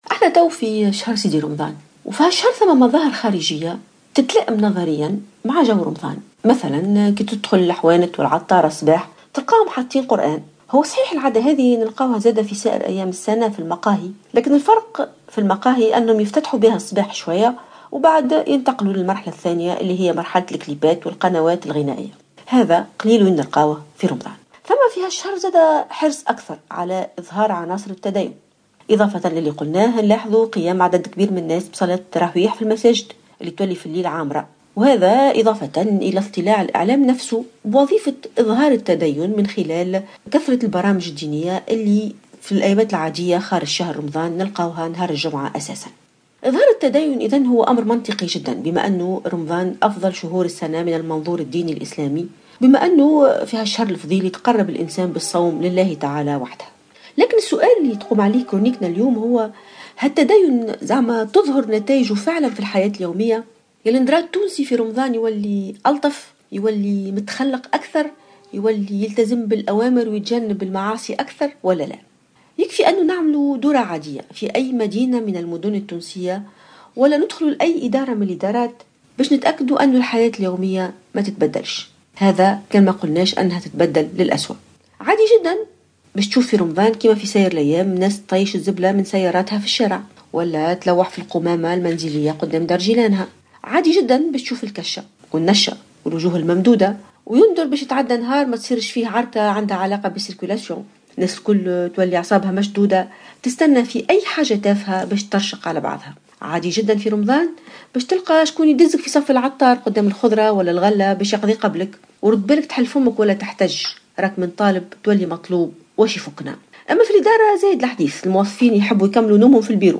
اعتبرت الكاتبة ألفة يوسف في افتتاحيتها على "الجوهرة أف أم" اليوم الخميس أن إظهار التديّن بمناسبة شهر رمضان أمر منطقي وتساءلت عما إذا كان لهذا التدين نتائج في الحياة اليومية للأشخاص بتجنّب المعاصي.